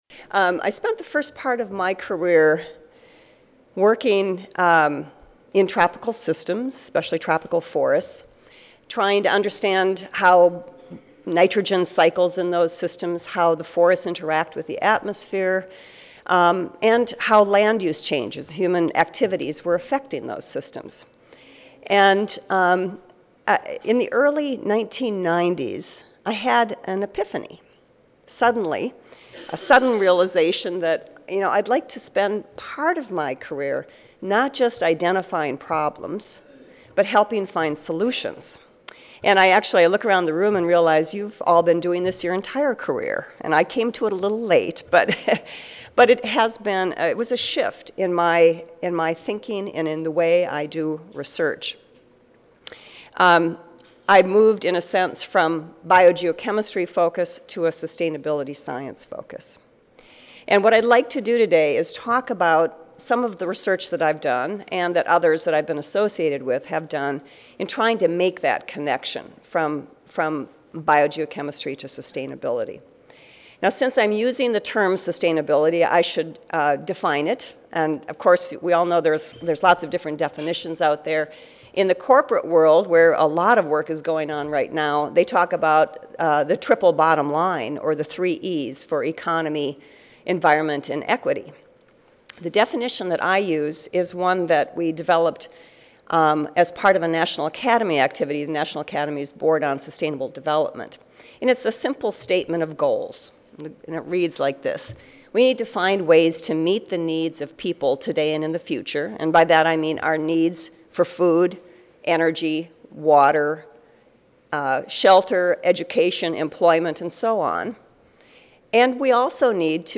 Other Daily Events Session: CSSA Breakfast and Awards/CSSA Plenary: Betty Klepper Endowed Lectureship (2010 Annual Meeting (Oct. 31 - Nov. 3, 2010))
Recorded presentation